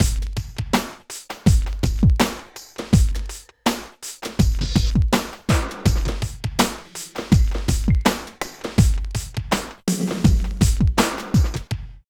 34 LOOP   -R.wav